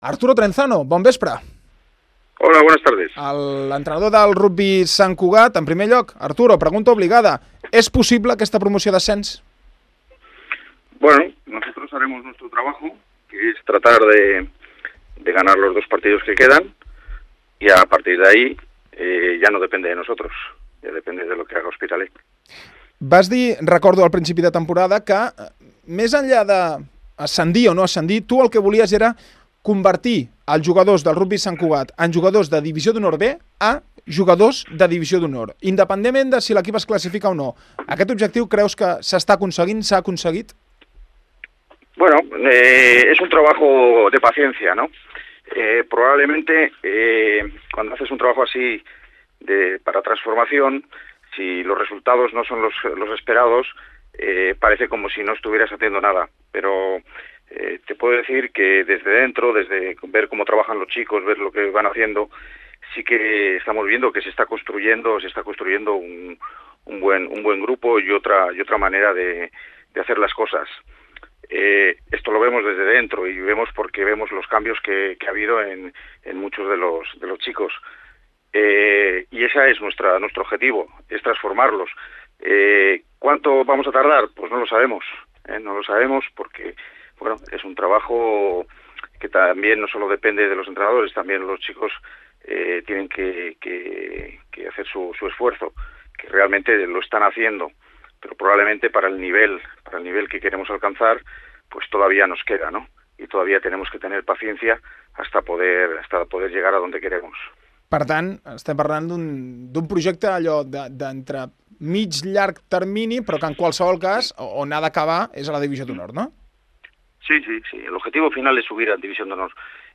Algunes de les frases més destacades de l'entrevista: